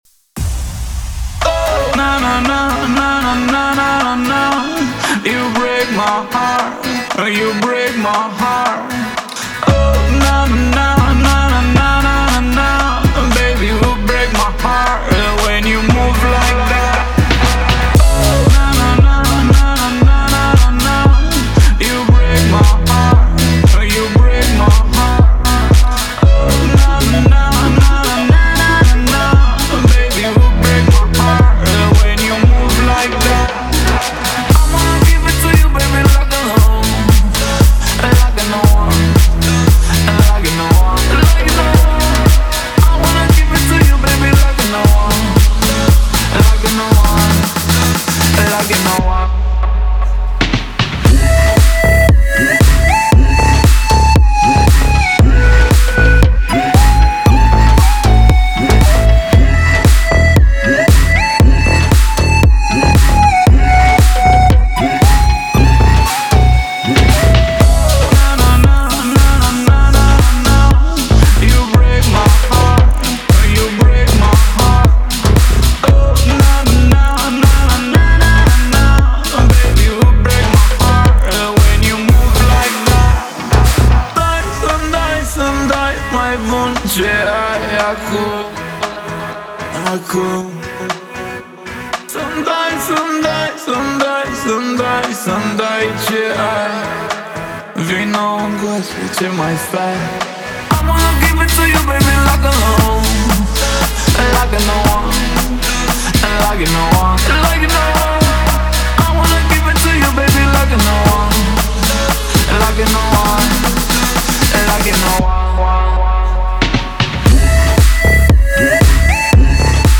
это энергичная танцевальная композиция в жанре электро-поп